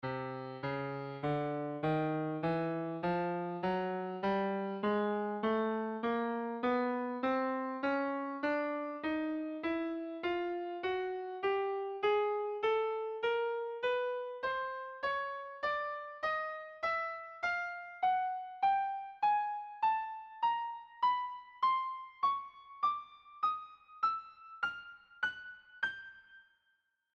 escala-cromatica1.mp3